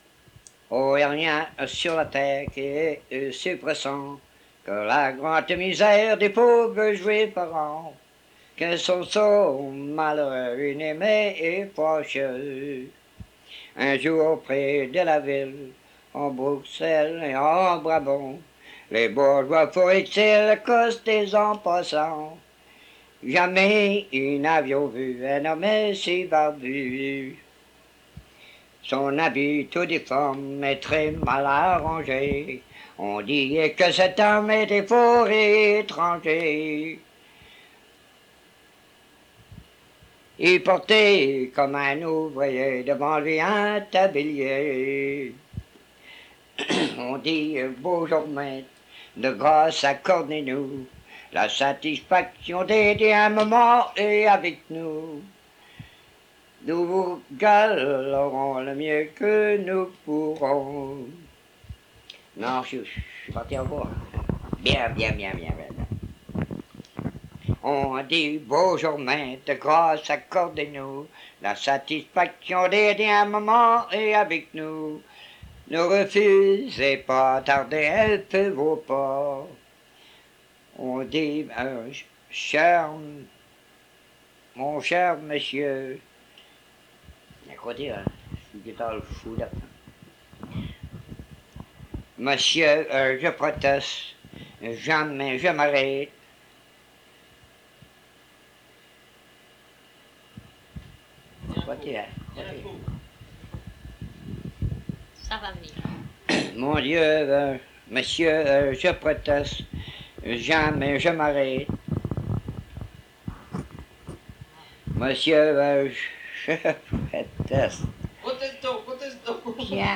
Chanson Item Type Metadata
Emplacement L'Anse-aux-Canards